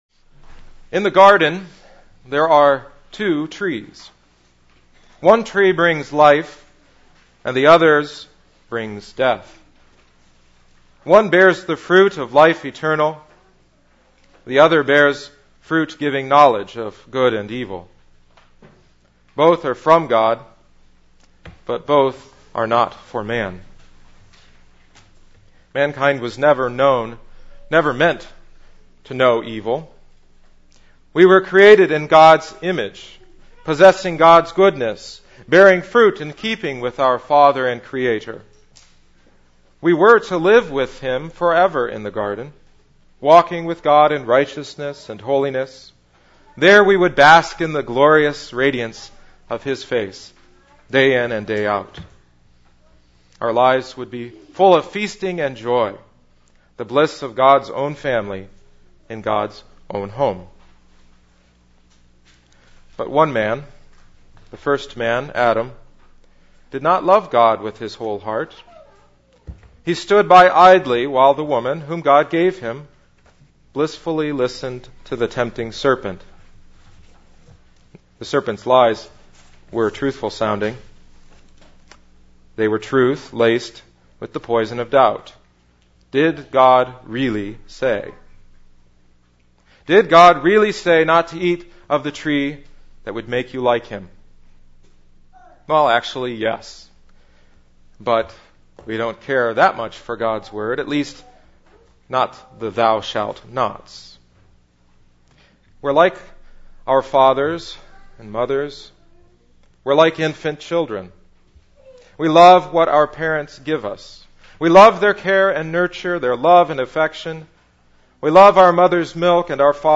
Easter Festival Service 2011 – Mark 16:1-8; Job 19:23-27; Psalm 118